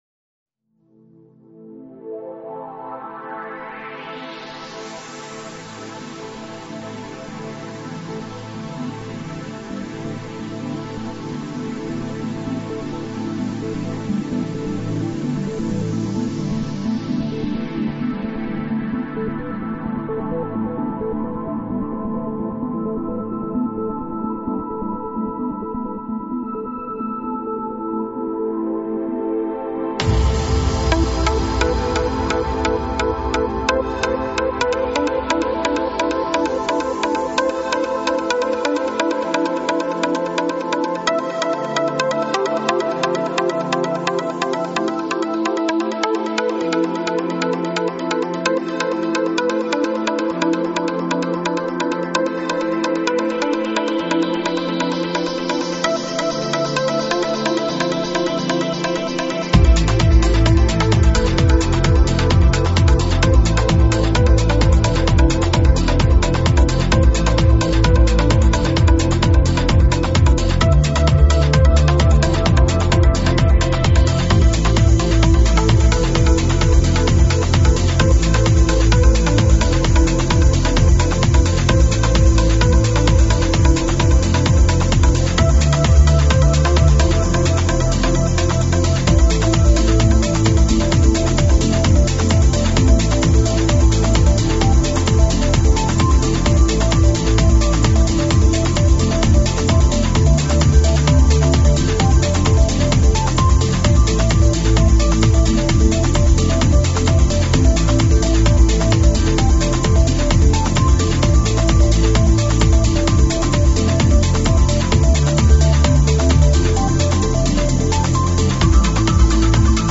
транс сборник